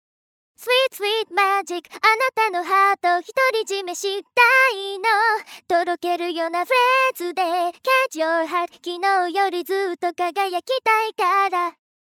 TOKYO6 ENTERTAINMENTの第二弾ソフトで、夏色花梨（なつき かりん）は元気で明るくパワフルな声質が特徴。
まずは、スタイルを何も適用していないデフォルトの歌声を聴いてください。